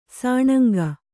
♪ sāṇanga